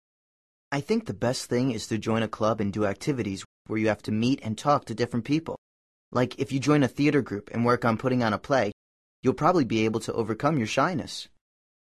Second speaker
Segundo orador